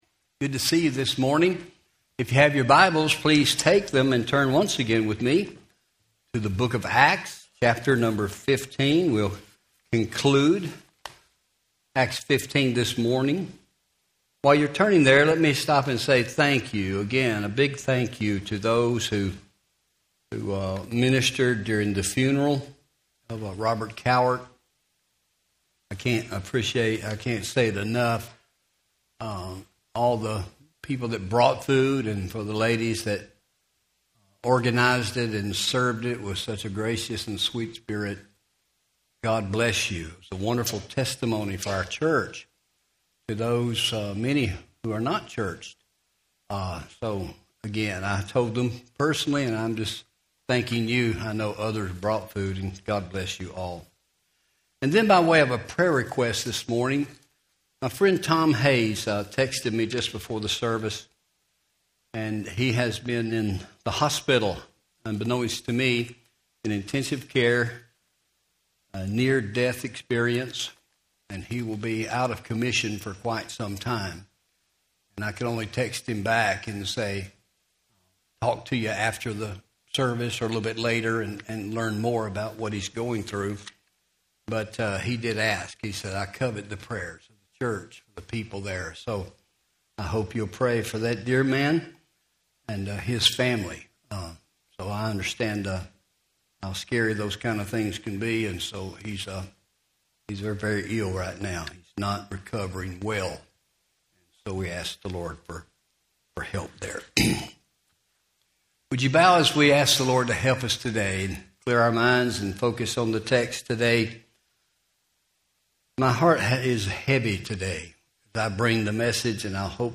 Home › Sermons › When Ministry Gets Messy Part 3